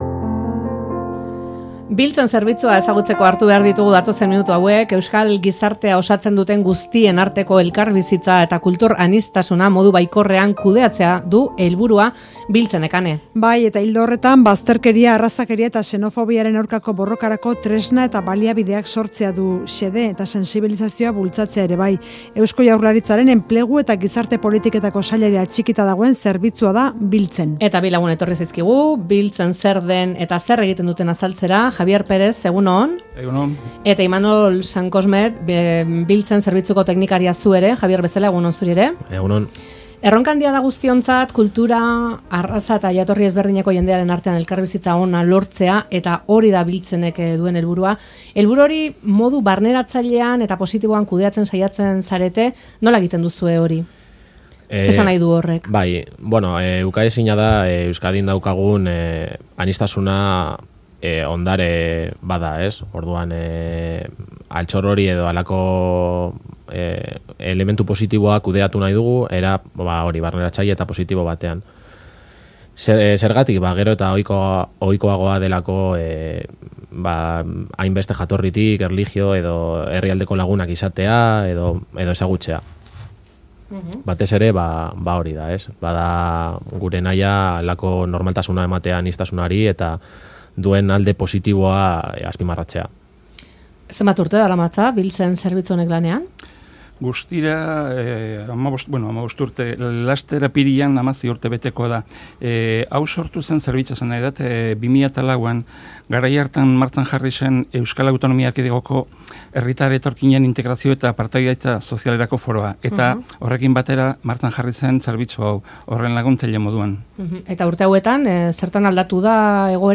Audioa: Aniztasuna ondarea delako bere alde baikorra azpimarratu nahi dute. Ezagutzeak beldurrak astintzen dituela nabarmendu digute Biltzen zerbitzuko bi teknikarik